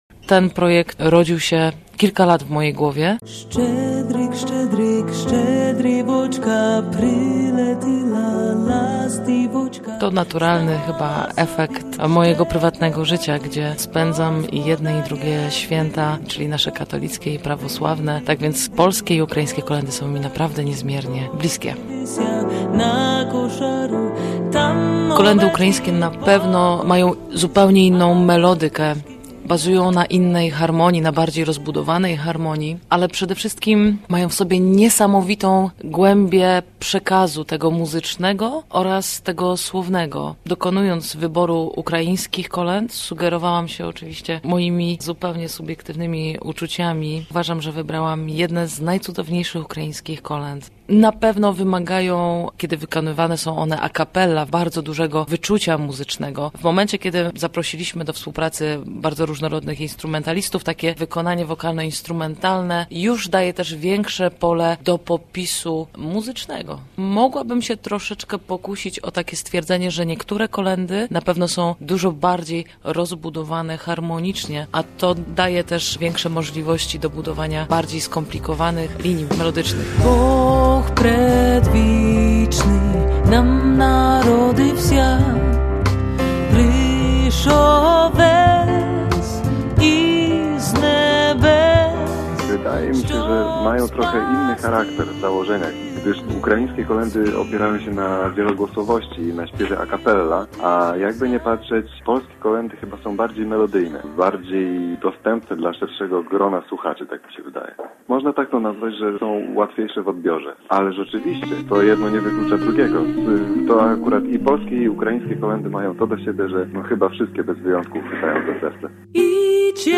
Jazzowe kolędy, polsko-ukraińskie